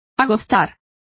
Complete with pronunciation of the translation of parches.